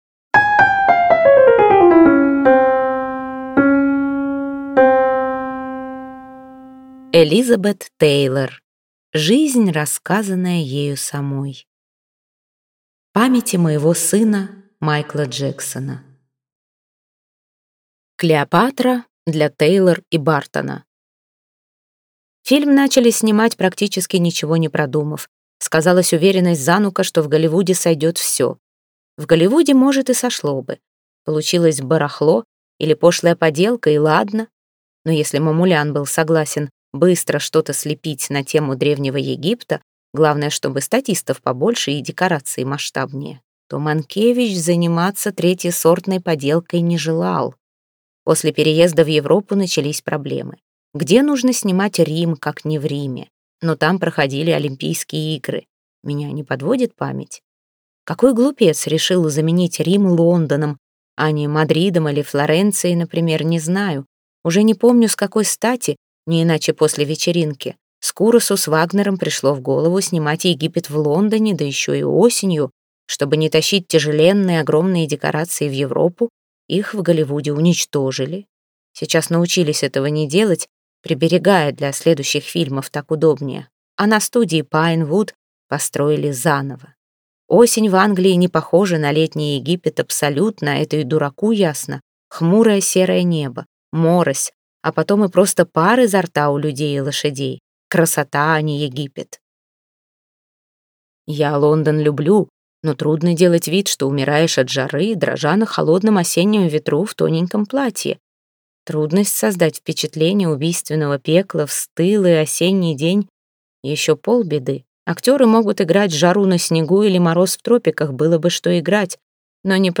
Аудиокнига Элизабет Тейлор. Жизнь, рассказанная ею самой | Библиотека аудиокниг